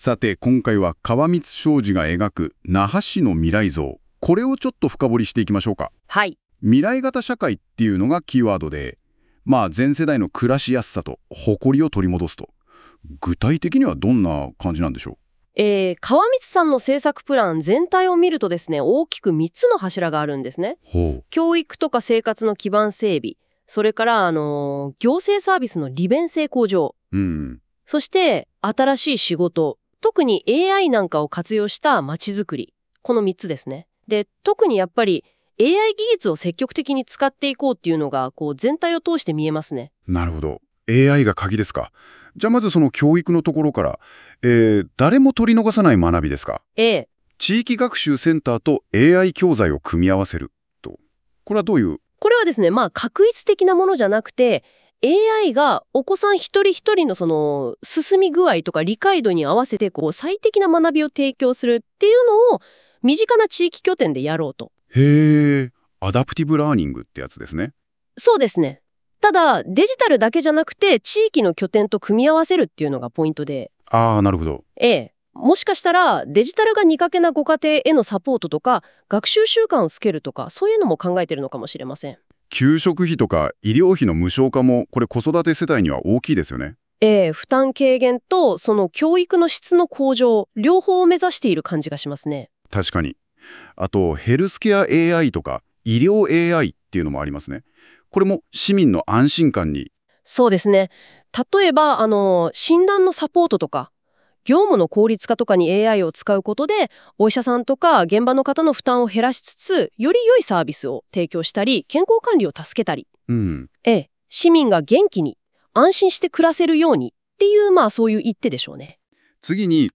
ON AIR